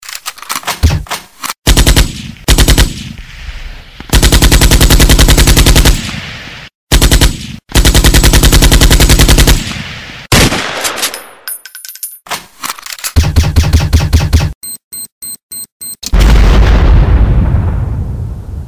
Kategori: Nada dering